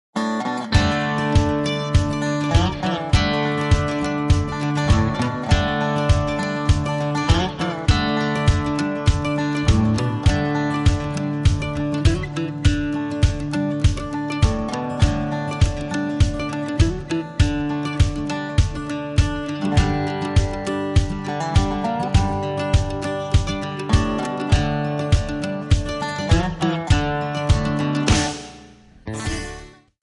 Backing track files: Country (2471)